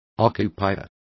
Complete with pronunciation of the translation of occupiers.